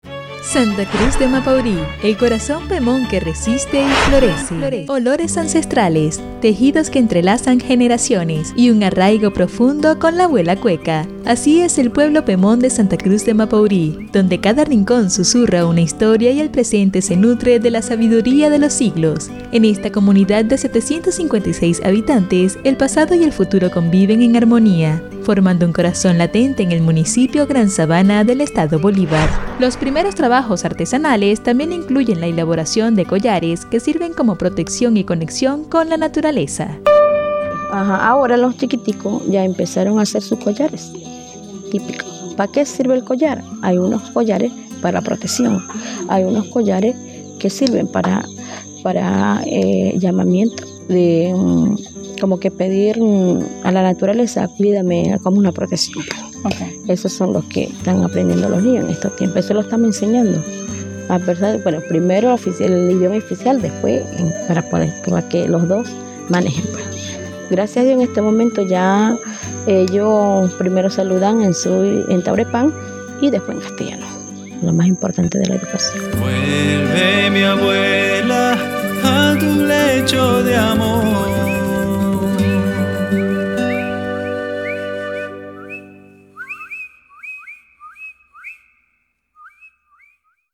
Micros radiales